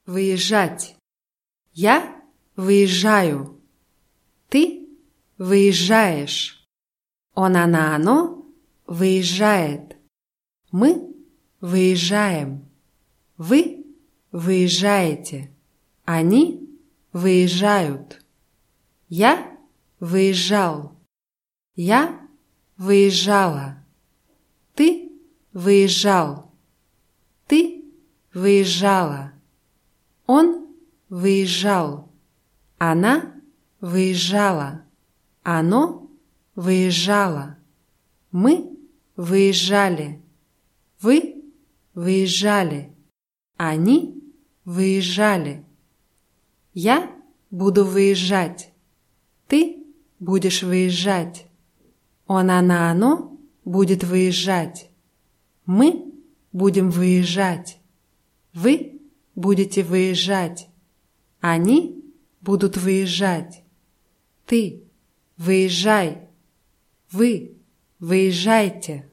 выезжать [wyʲischschátʲ]